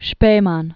(shpāmän), Hans 1869-1941.